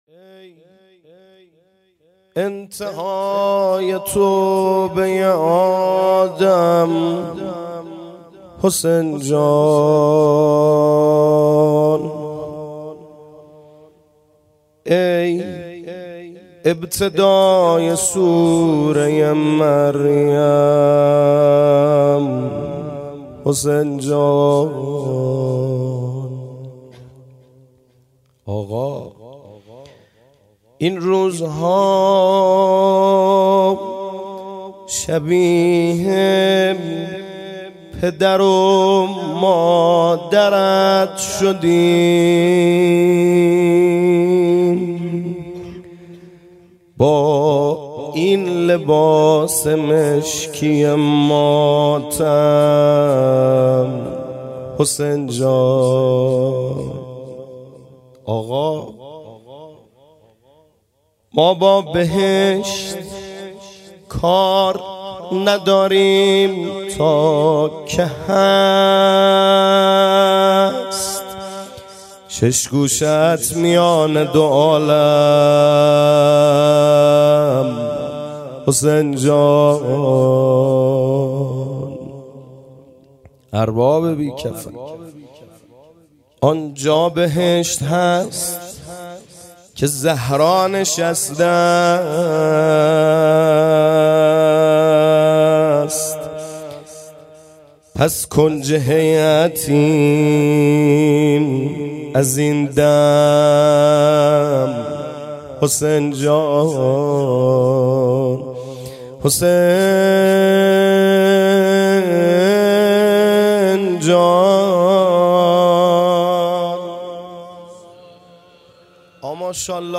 گزارش صوتی شب چهارم محرم 98 | هیأت محبان حضرت زهرا سلام الله علیها زاهدان